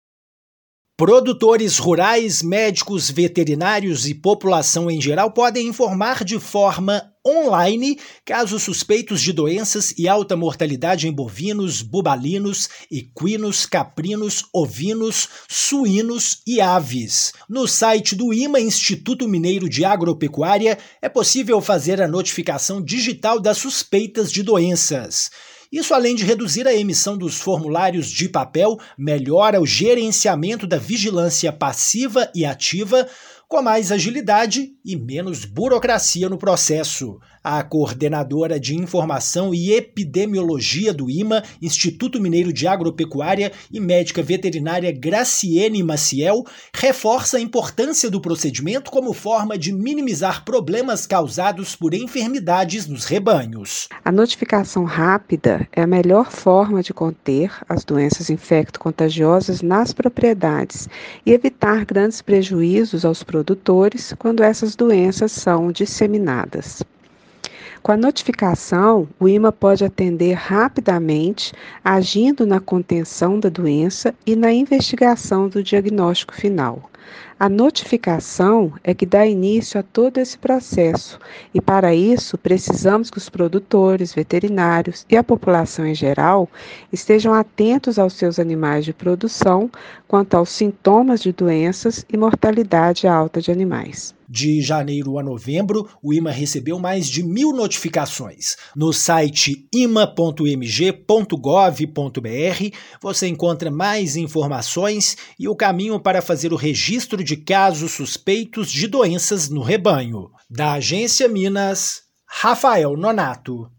[RÁDIO] Notificações on-line contribuem para o controle sanitário dos rebanhos mineiros
Produtores rurais, médicos veterinários e população em geral podem informar, de forma on-line, casos suspeitos de doenças e alta mortalidade em bovinos, bubalinos, equinos, caprinos, ovinos, suínos e aves. Ouça a matéria de rádio.
MATÉRIA_RÁDIO_NOTIFICAÇÕES_ONLINE_IMA.mp3